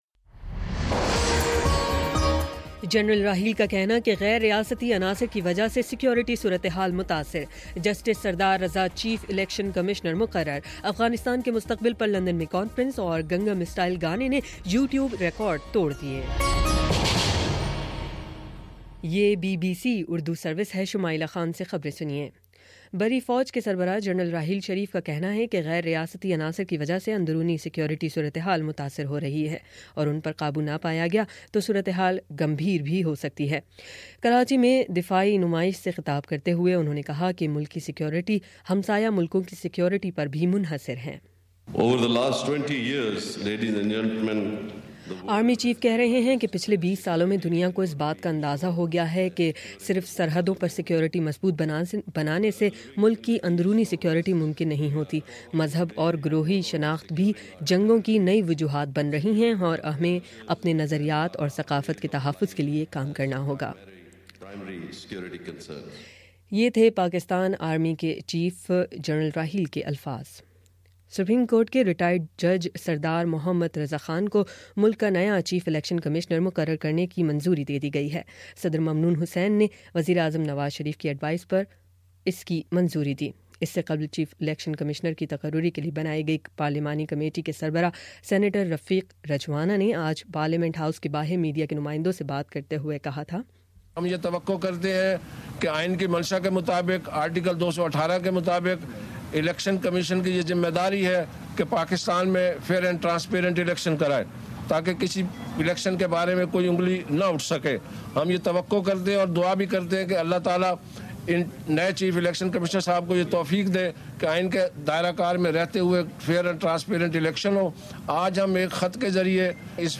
دسمبر 04 : شام چھ بجے کا نیوز بُلیٹن